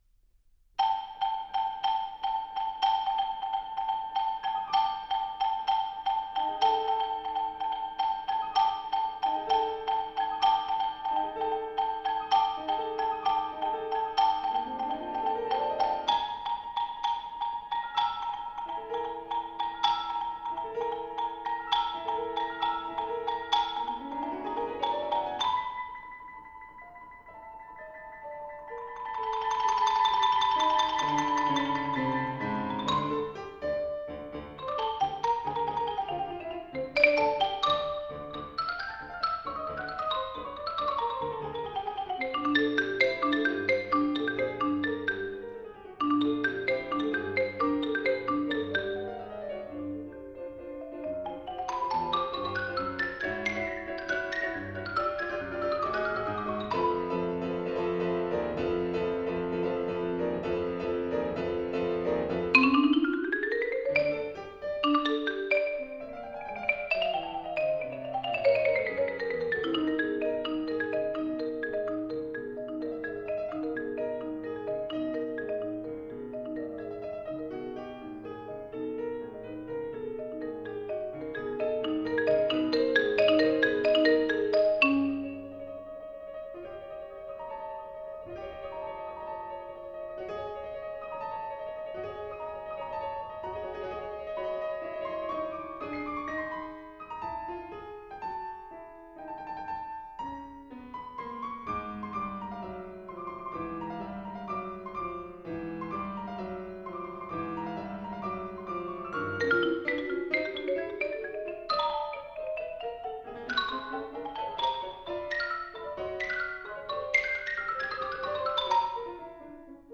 マリンバ、打楽器奏者。
ピアノ伴奏